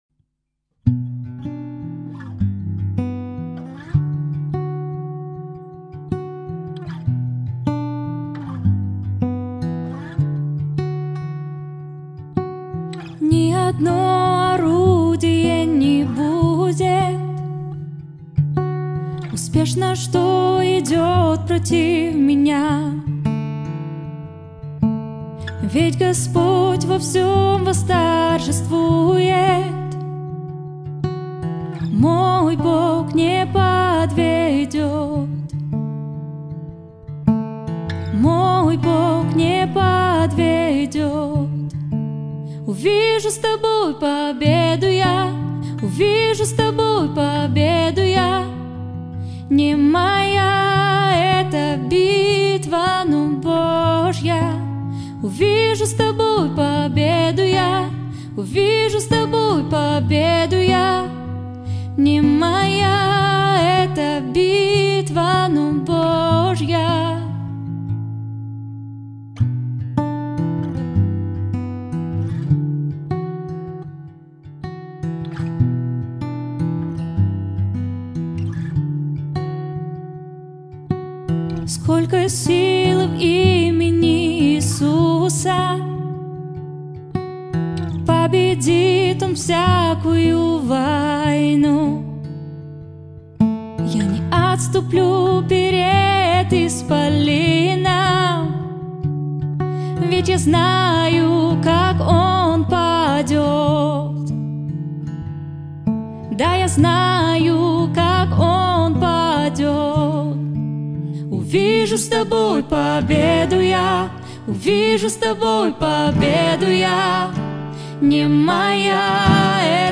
922 просмотра 26 прослушиваний 1 скачиваний BPM: 155